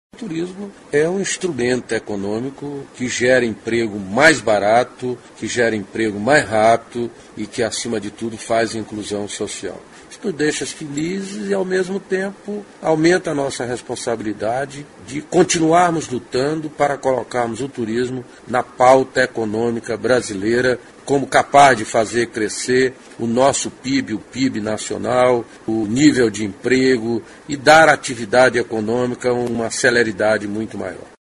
aqui e ouça declaração do ministro Gastão Vieira sobre a importância do turismo para a geração de emprego e renda.